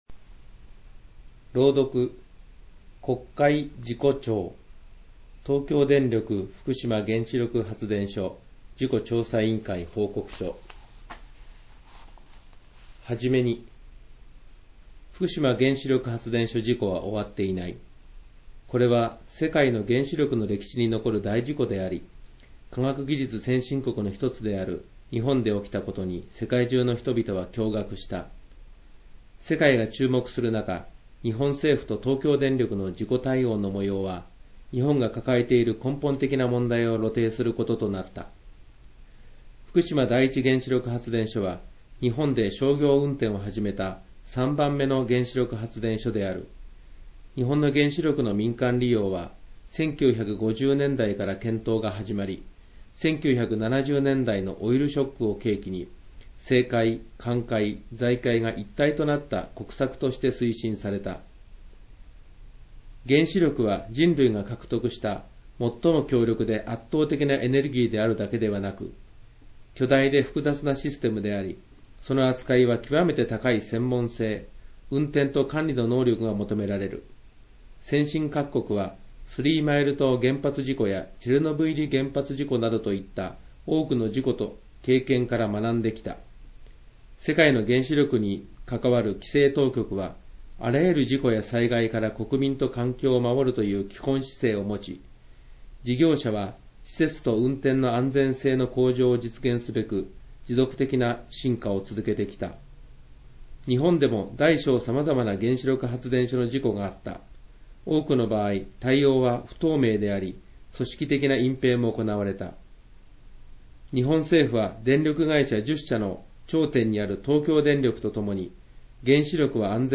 国会事故調の報告書、読み上げ音声データらしい。